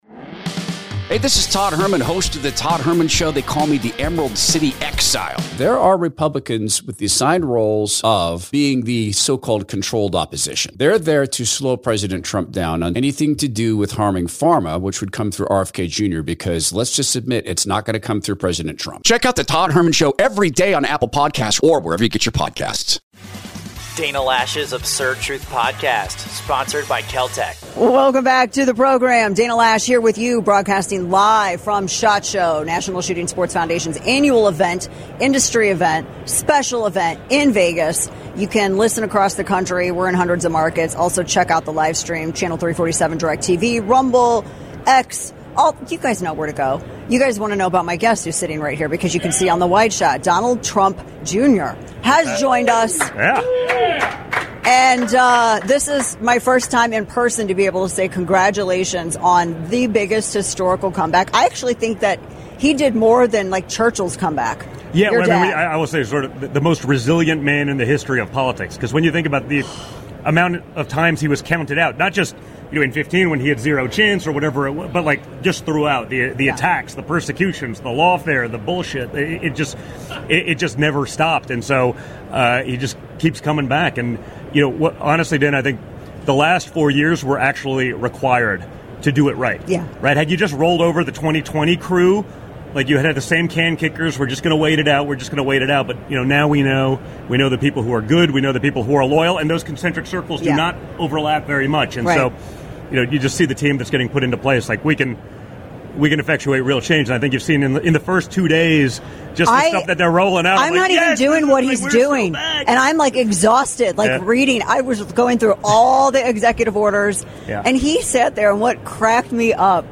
Donald Trump Jr. joins us live to talk about his father's long list of executive orders, the work of his strong daughter, Kai, national reciprocity, and whether he will run for office.